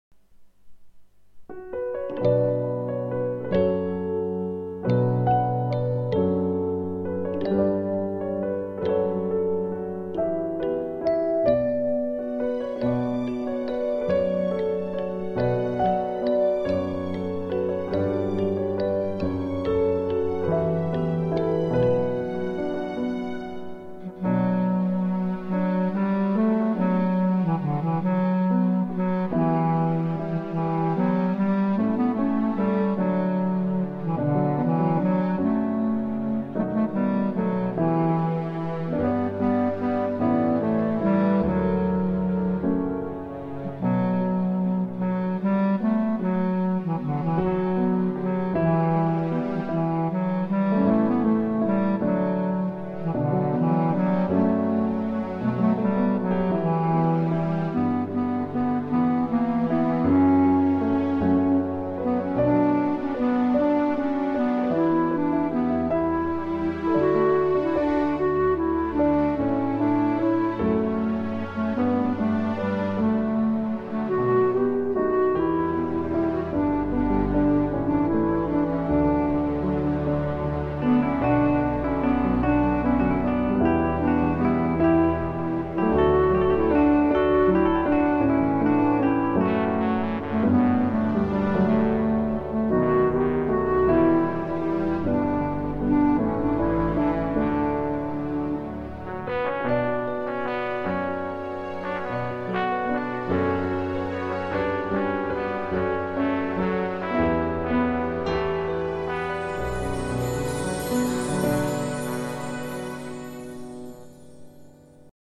השיר הוקלט עם אורגנית (כמה הקלטות אחת על השנייה.)